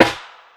Combo Snare.wav